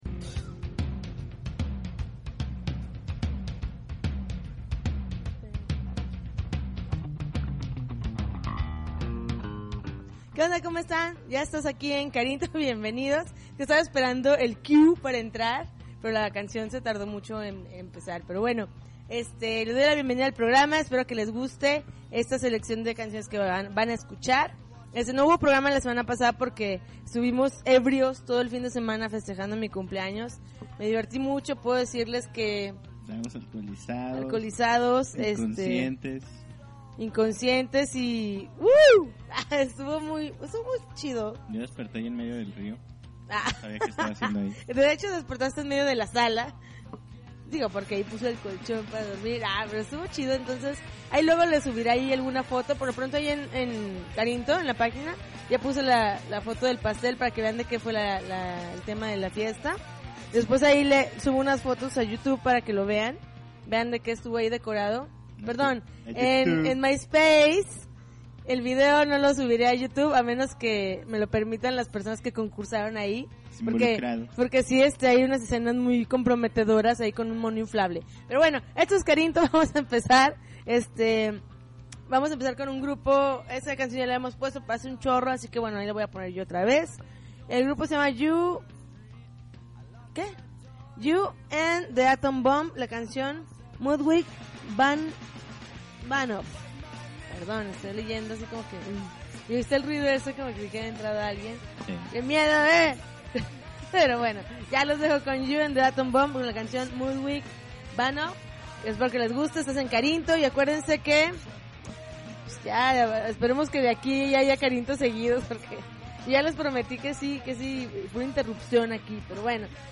February 1, 2009Podcast, Punk Rock Alternativo